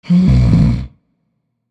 inhale.ogg